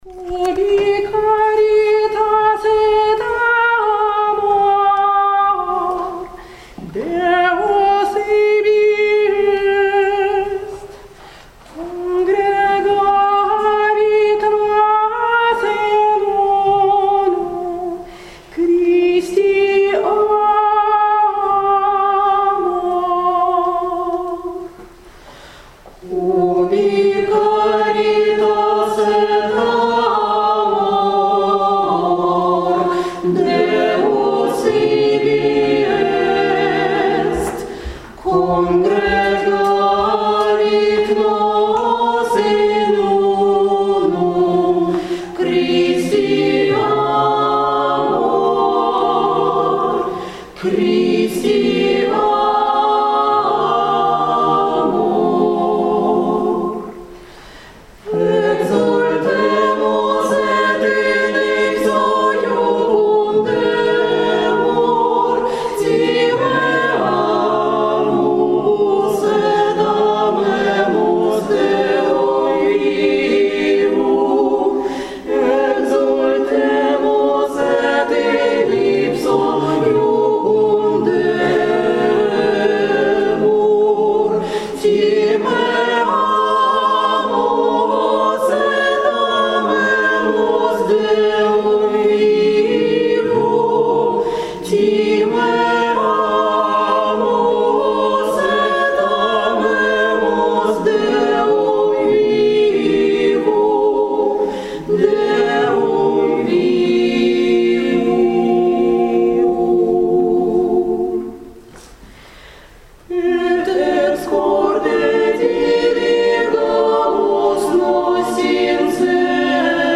Месса Воспоминания Вечери Господней в главном храме Преображенской епархии (+ ФОТО)
Pesnopenie-v-ispolnenii-prihodskogo-hora-Kafedralnogo-sobora.mp3